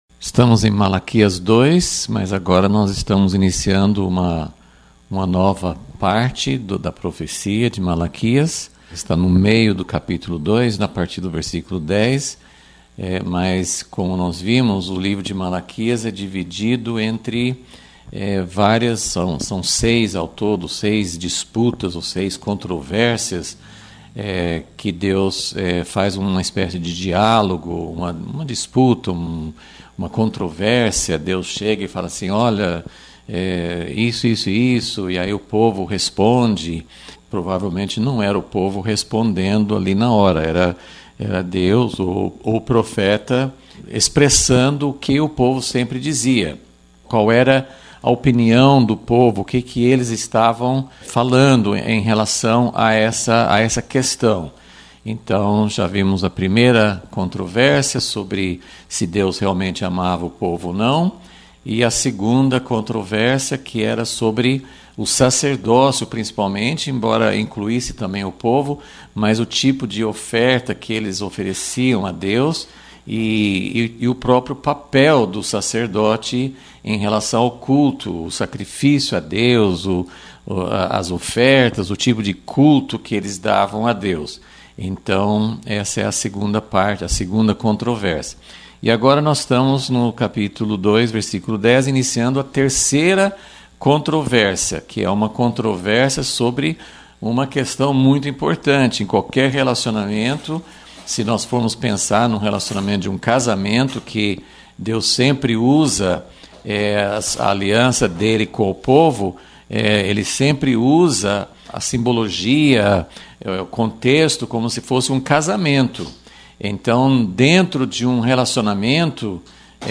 Aula 9 – Vol.37 – Infidelidade no casamento é infidelidade a Deus